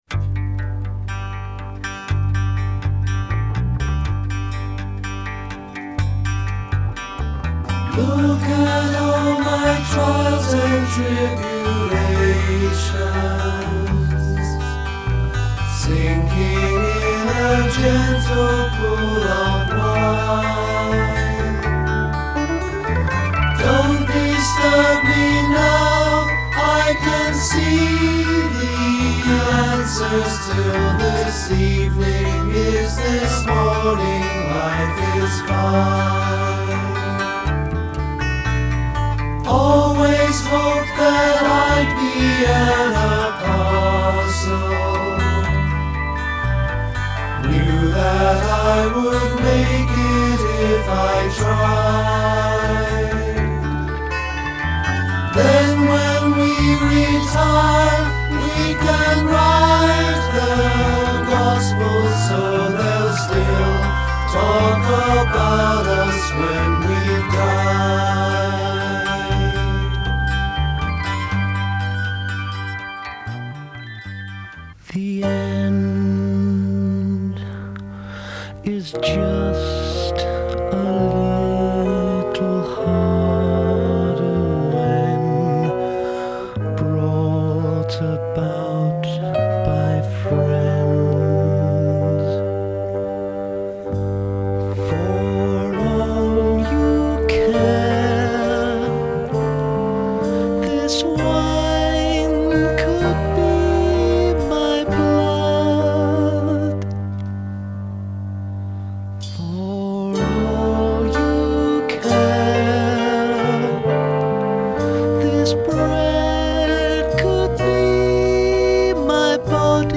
Genre : Musical Theatre